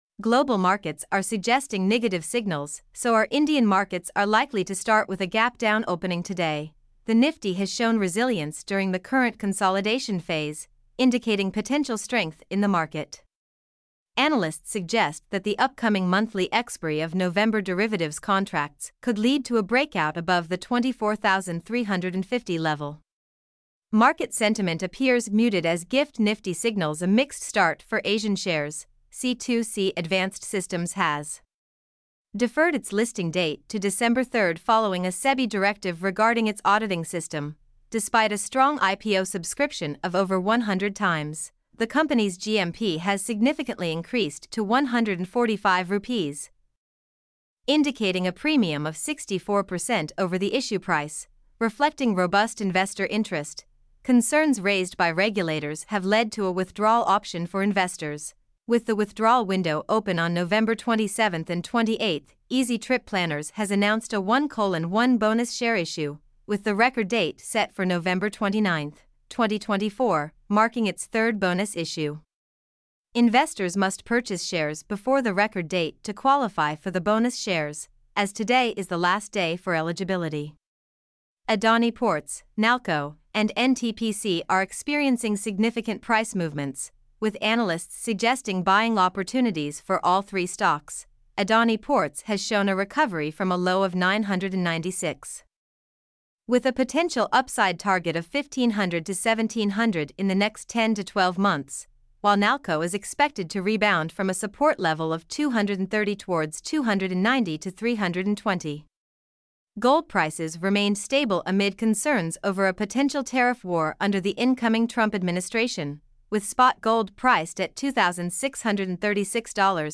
mp3-output-ttsfreedotcom-7.mp3